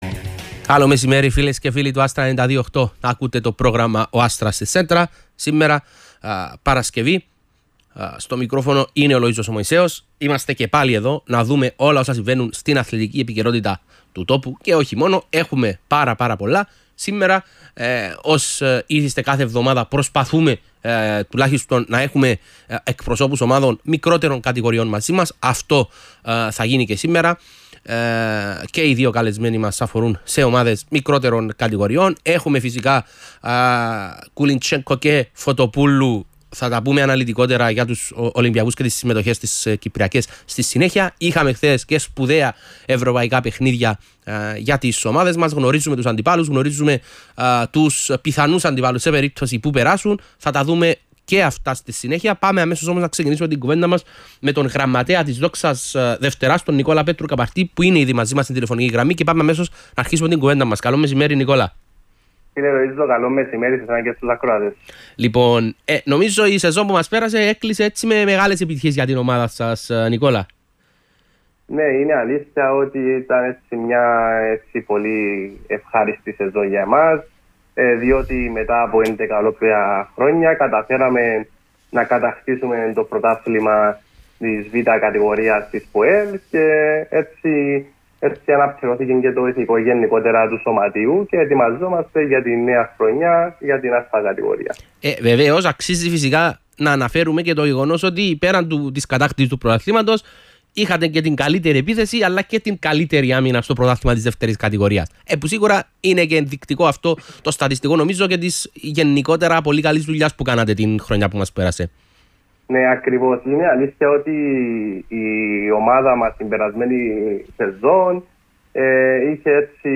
ραδιοφωνικές δηλώσεις